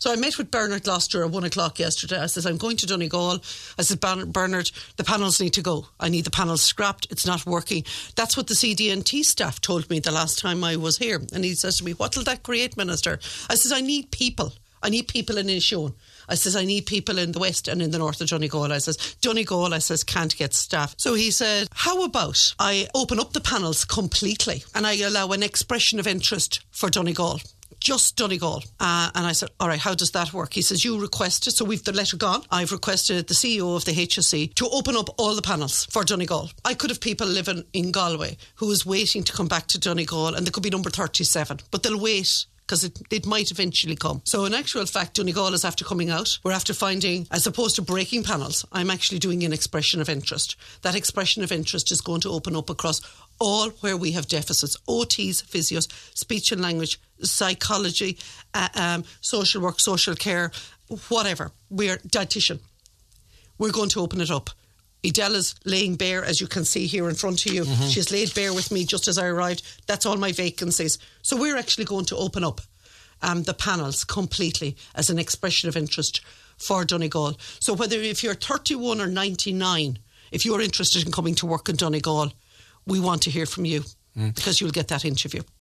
She revealed the details on Highland Radio ahead of a meeting: